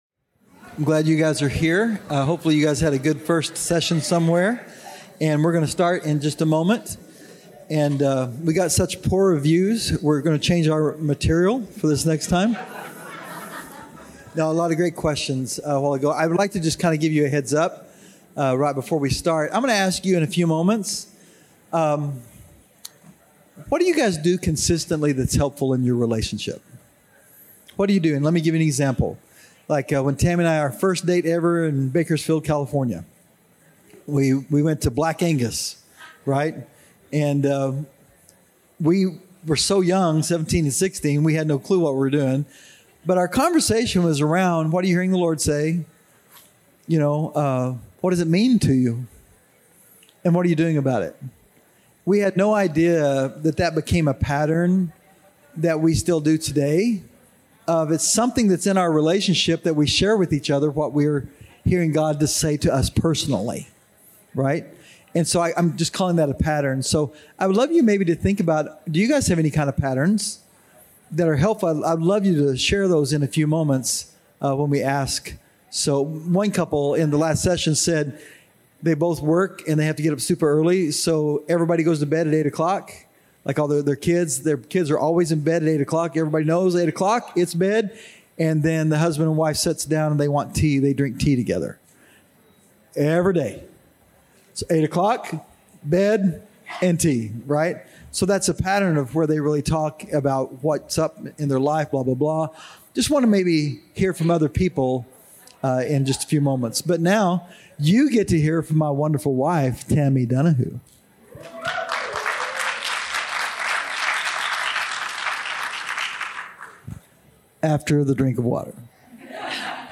in this workshop from Foursquare Connection 2023.